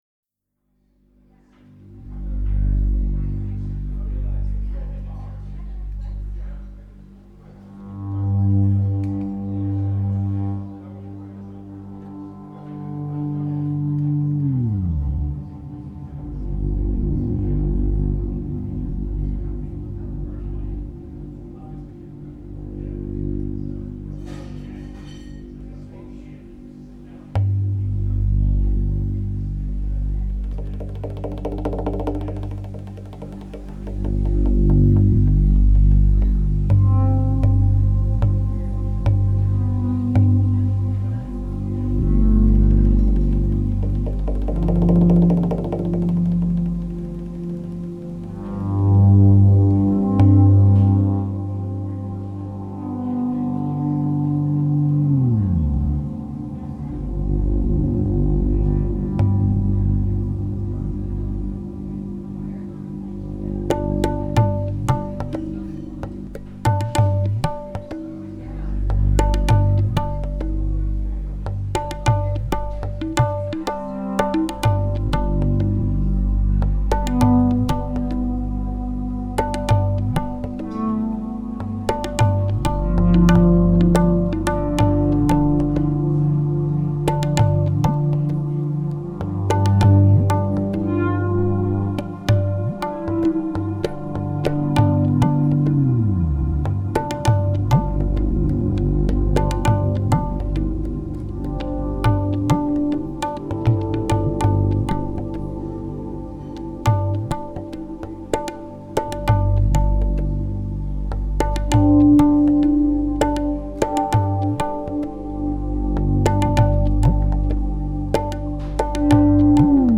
Live at the Gandy Dancer 6/1/02